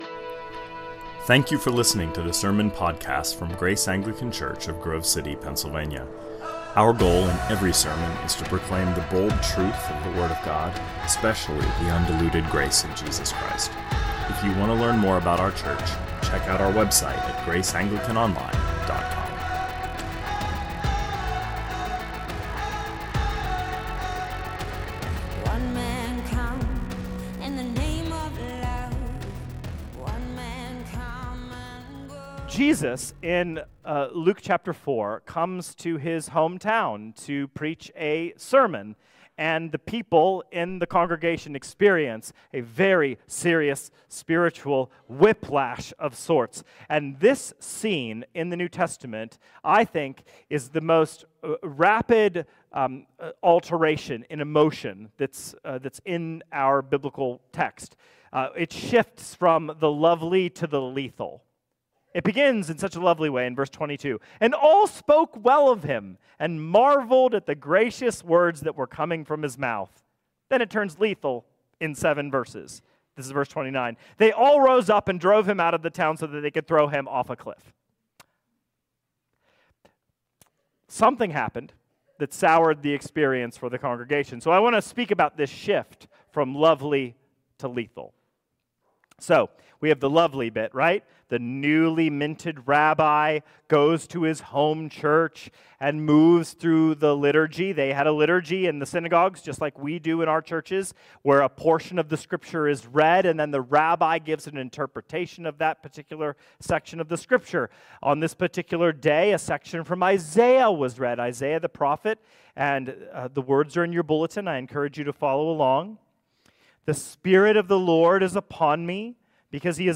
2025 Sermons